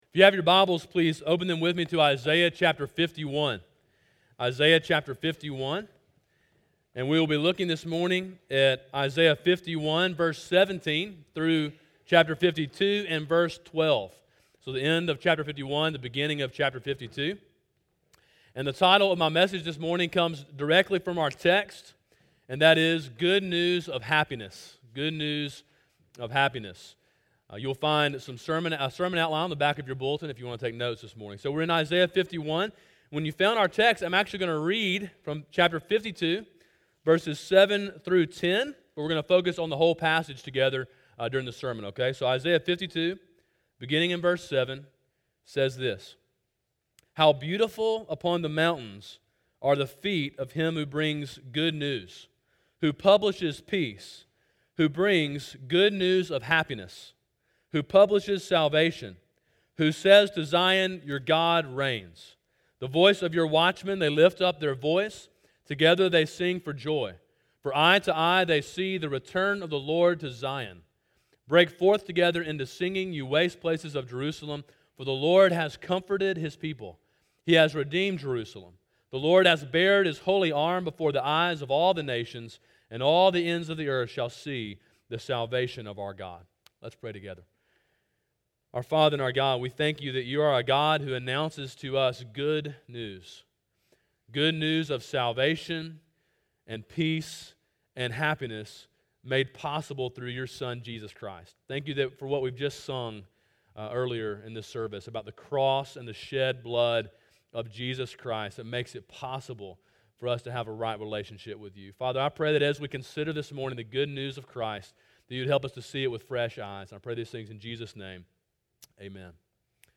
Sermon: “Good News of Happiness” (Isaiah 51:17-52:12) – Calvary Baptist Church